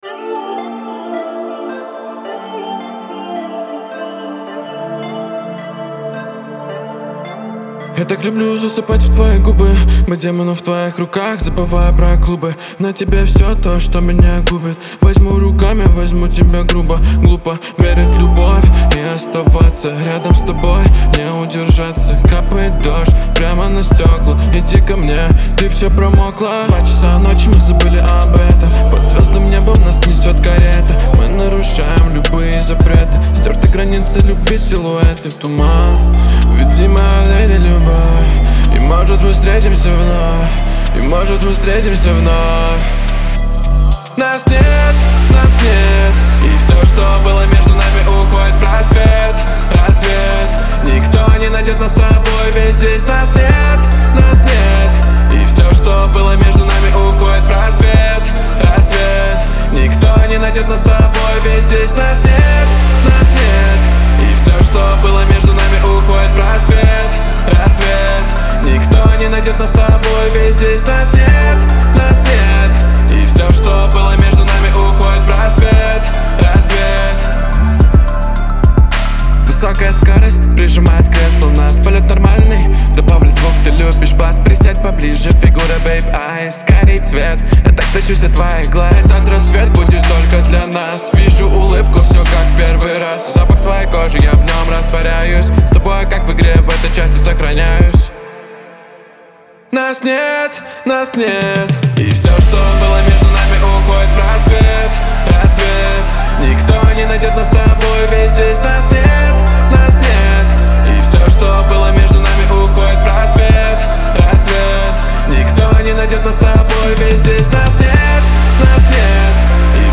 это мелодичная и атмосферная композиция в жанре поп